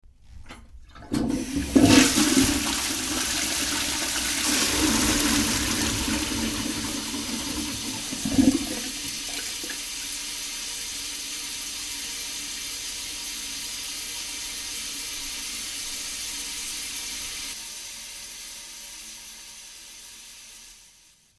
Toilet flush
Tags: Travel Sounds of Austria Austria Holidays Vienna